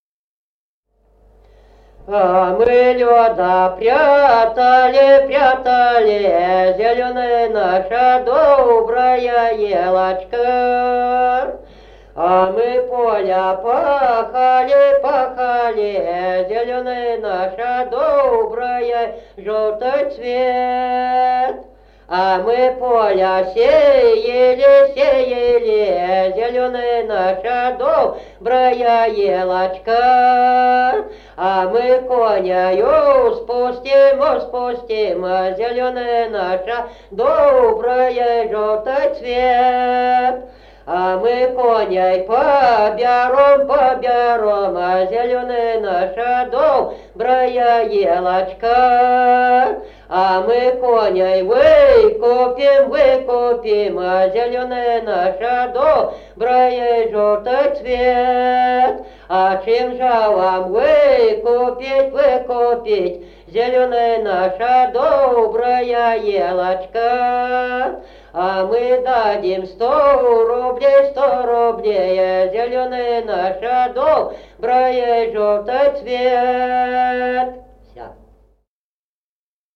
Народные песни Стародубского района «А мы лёдо прятали», юрьевская таночная.
1953 г., д. Камень.